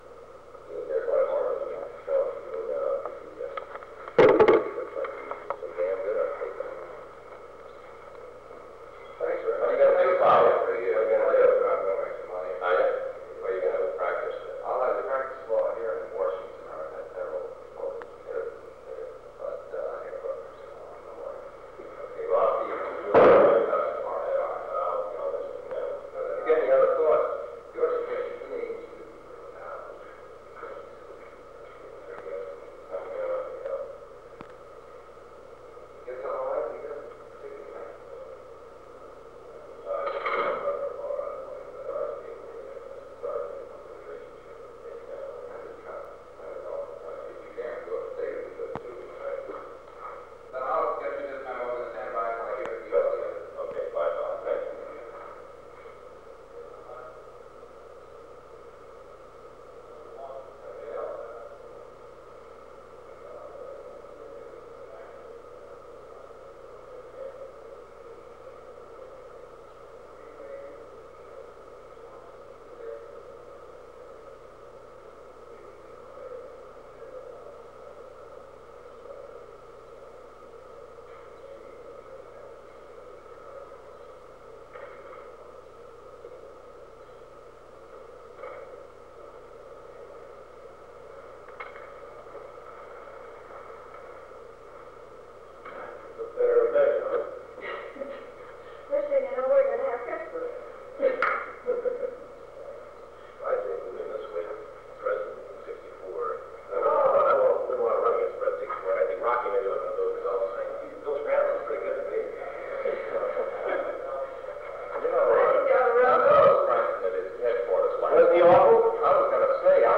Informal Office Conversations
Secret White House Tapes | John F. Kennedy Presidency Informal Office Conversations Rewind 10 seconds Play/Pause Fast-forward 10 seconds 0:00 Download audio Previous Meetings: Tape 121/A57.